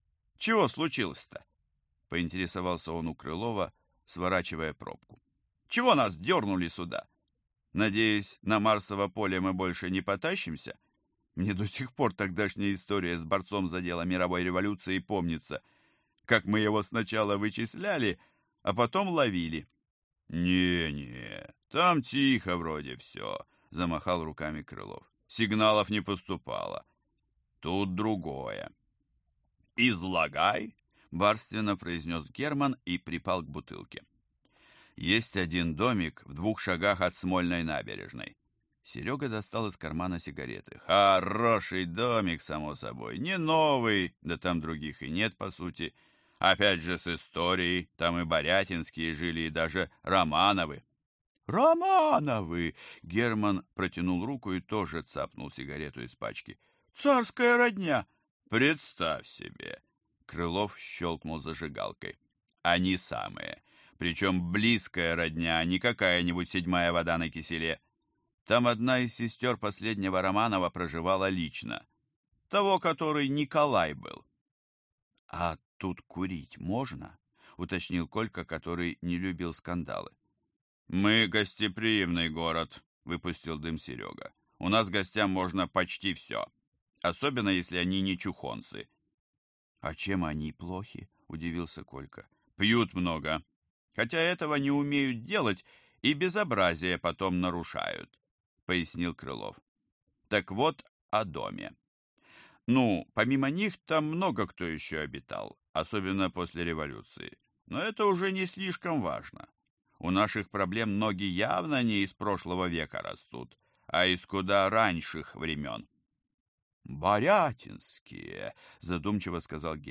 Аудиокнига Отдел «15-К». Сезон 1. Выпуск 5 | Библиотека аудиокниг